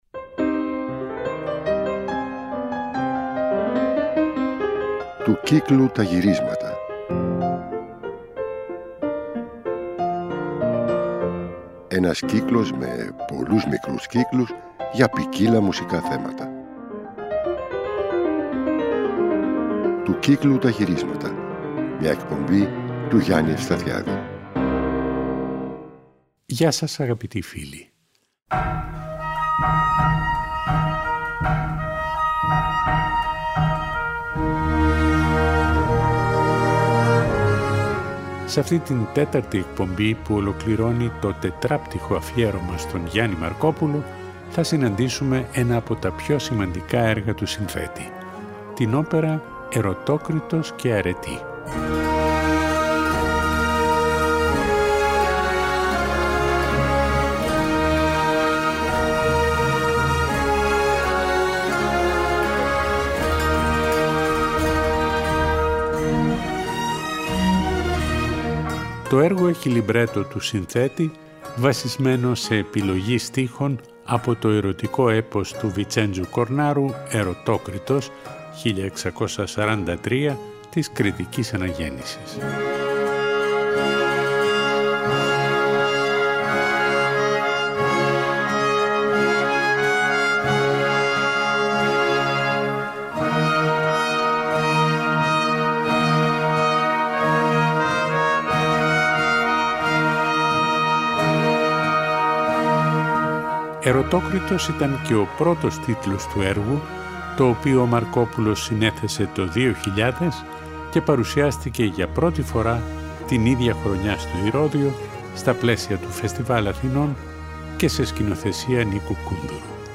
Στις εκπομπές αυτές θα ακουστούν και οι λιγότερο γνωστές συνθέσεις αυτού του πολύμορφου και πολυφωνικού έργου.
Τραγούδια, κινηματογραφικές μουσικές, ραψωδίες, πυρρίχιοι χοροί, κοντσέρτα, μουσική δωματίου, ορατόρια, όπερες ακόμα και ανέκδοτα έργα του.